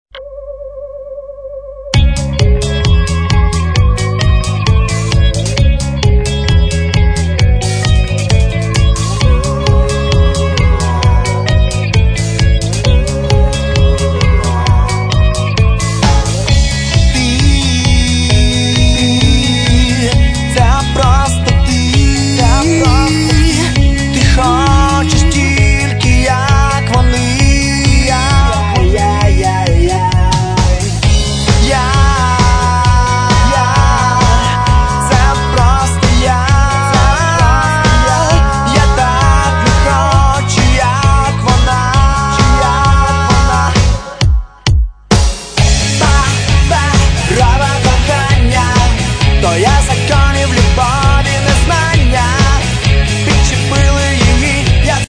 Home » CDs» Rock My account  |  Shopping Cart  |  Checkout